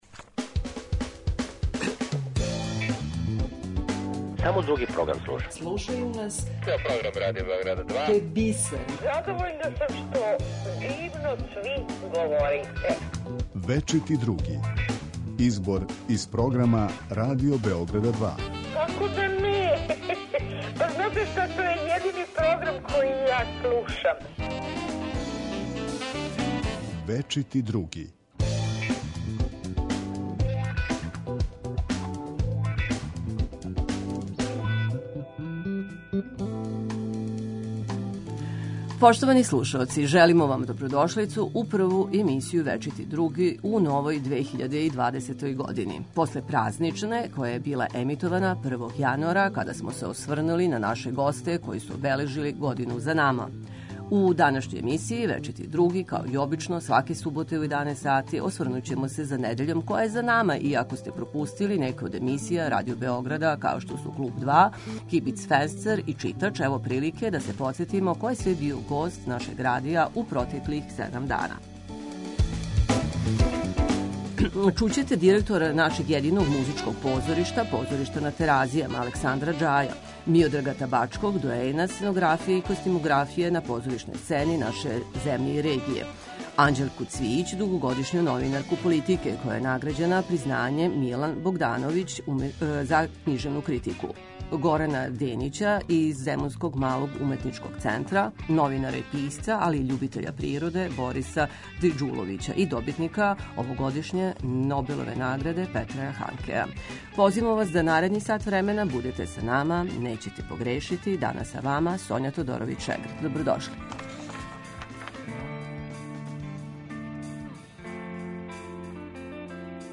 У емисији 'Вечити други' чућете најзанимљивије делове из програма Радио Београд 2. Издвојили смо емисије 'Клуб 2', 'Кибицфенстер' и 'Читач'.